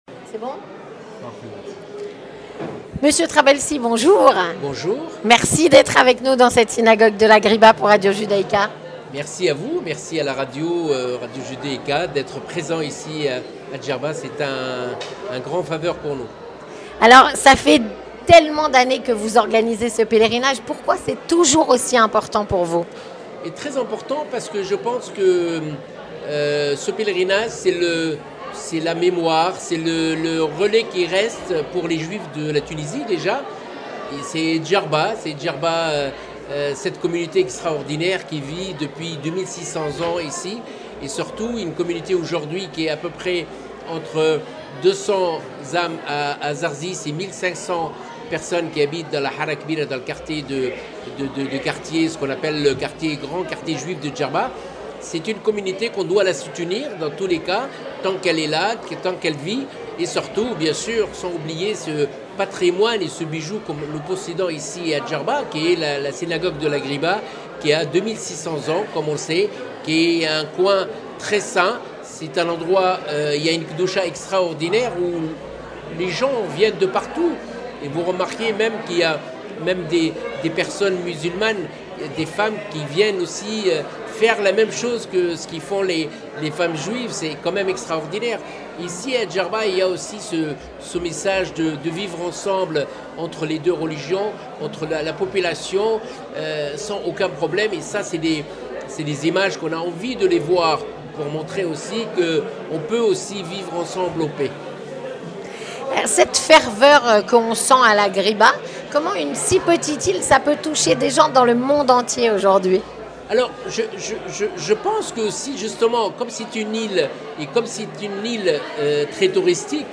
Entretien du 18H - Avec René Trabelsi ancien ministre du tourisme tunisien et organisateur du pèlerinage de la Ghriba (09/05/2023)